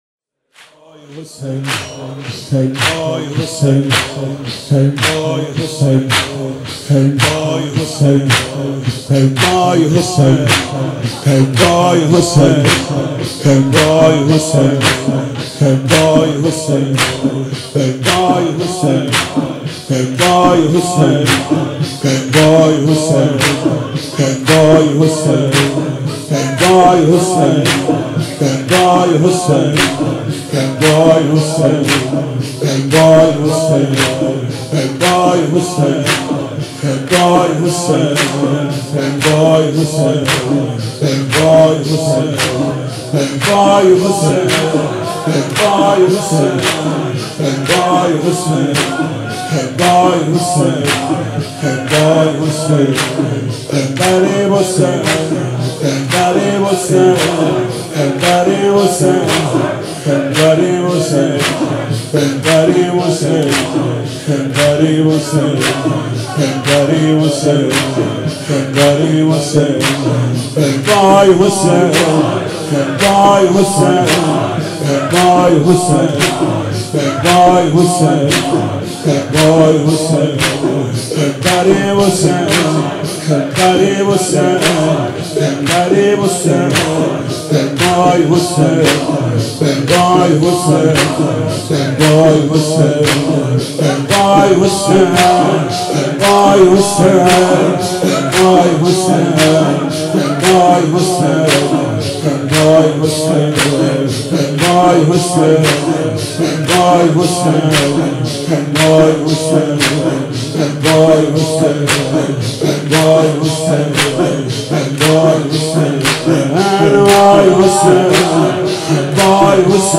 مداح : محمود کریمی قالب : شور